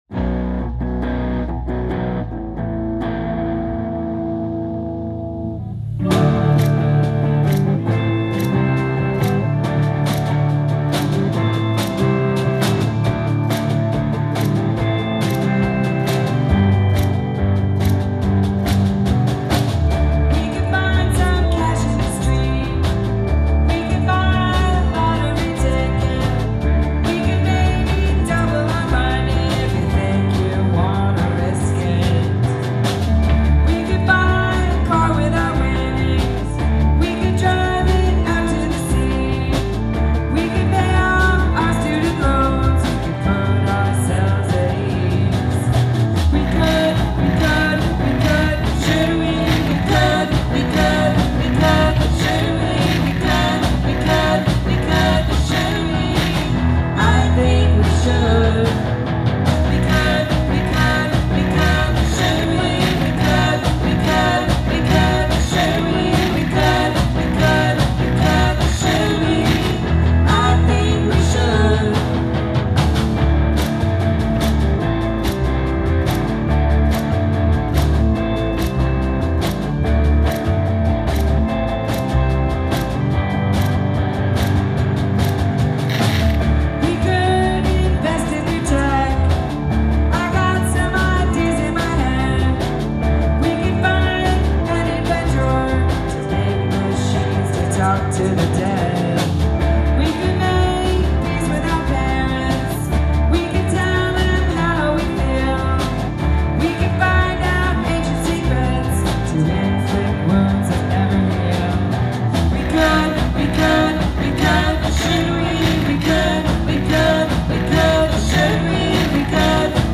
Have a guest play a household item on the track